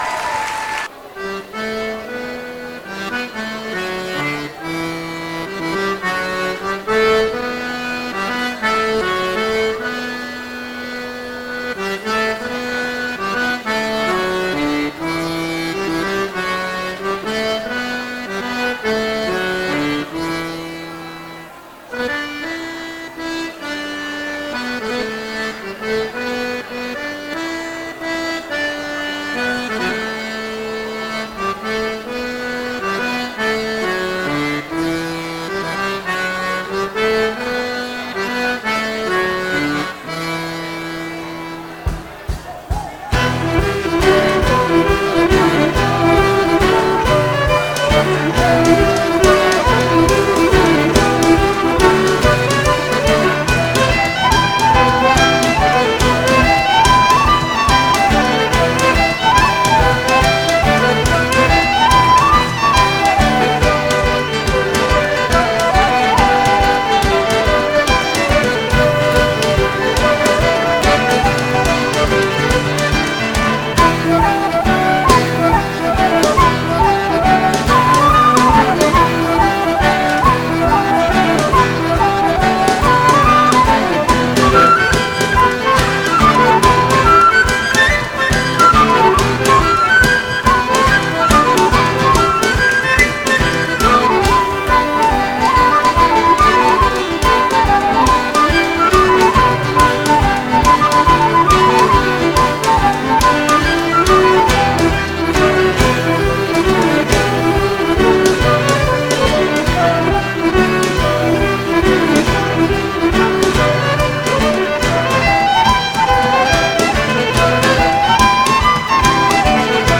Butterfly (par Draft Company) (Slip jig) - Musique irlandaise et écossaise
C'est un enregistrement fait au cours d'un bal, donc pour la danse.. Auteur : Traditionnel irlandais.